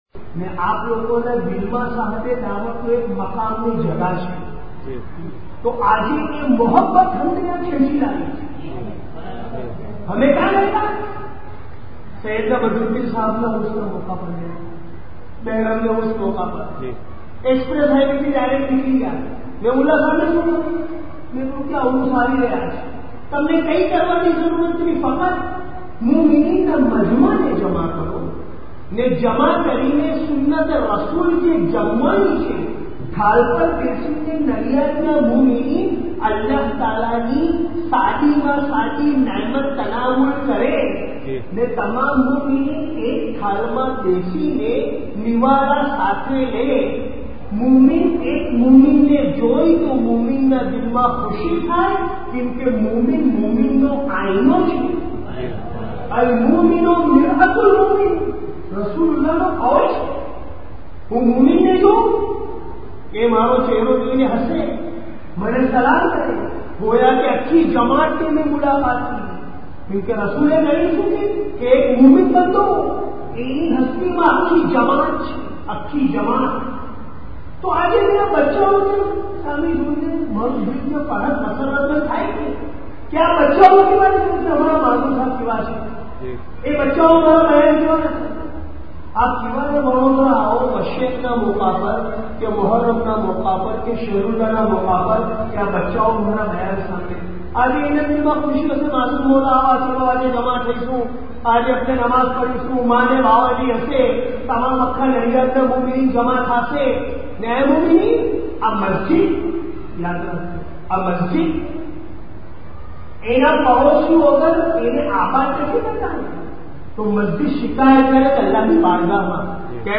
Bayaan of Mazoon-e-Mutlaq Saiyedi saheb (dm) at Faatemi Masjid : Nadiad (19 Rabi II 1431) (audio)
nadiad mazoon e mutlaq bayaan rabi II 1431.mp3